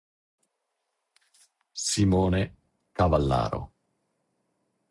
Hear name pronounced.